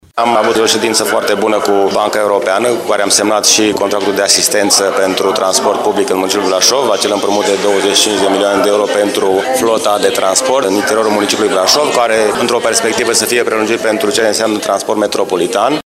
RATBV SA își mărește parcul auto cu 105 autobuze EURO 6 pe care le va achiziționa cu ajutorul unui împrumut de 114 milioane de lei contractat cu BERD, spune primarul municipiului Brașov, George Scripcaru: